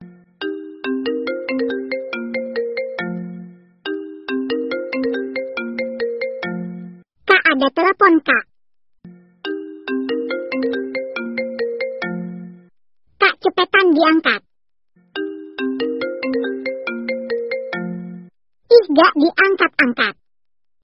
Kategori: Nada dering
Keterangan: Download nada dering Ada Telepon versi wanita, Unduh nada dering Kak, ada telepon Kak.